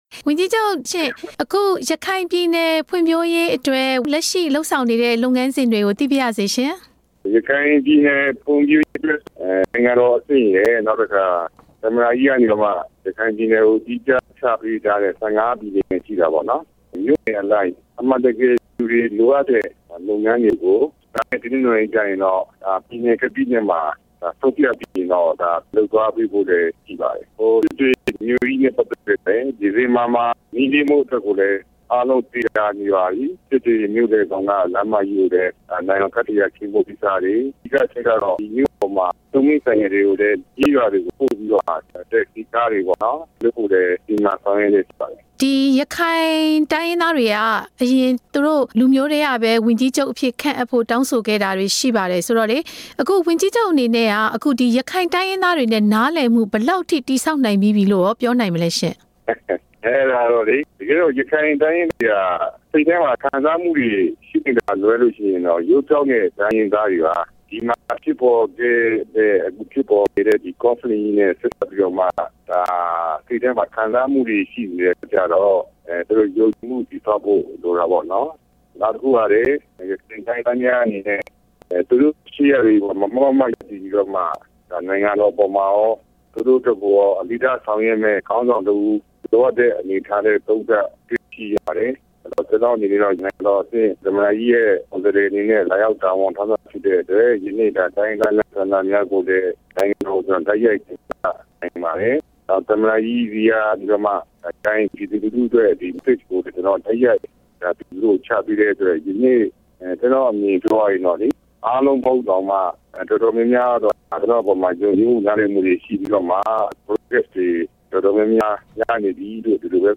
ဝန်ကြီးချုပ် ဦးမောင်မောင်အုန်းနဲ့ မေးမြန်းချက်